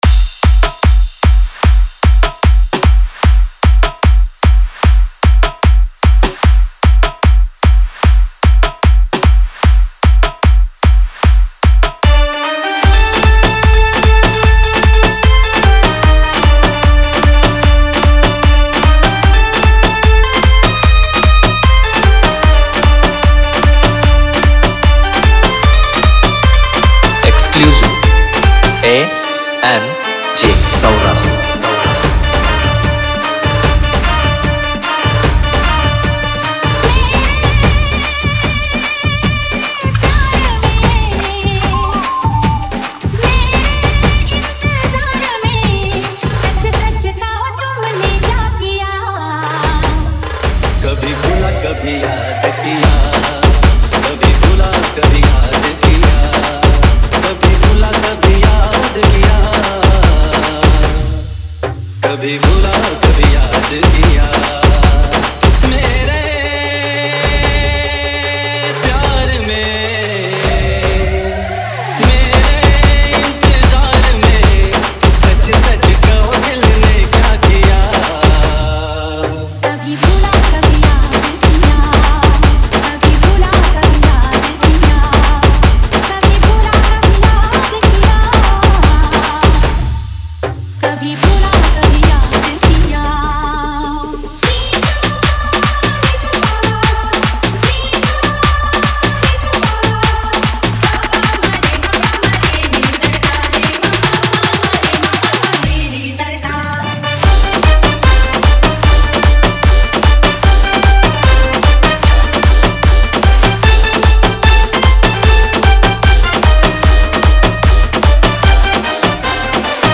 Marathi Dj Single 2025